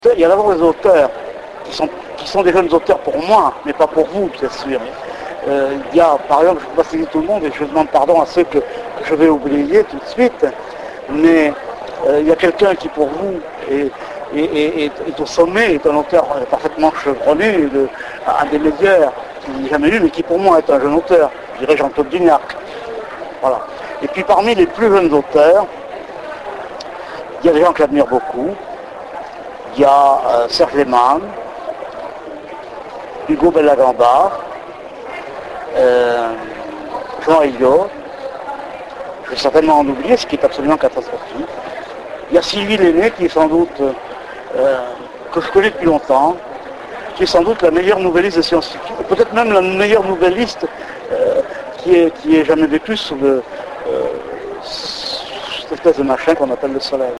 Interview Michel Jeury - Mai 2007
La réponse de Michel Jeury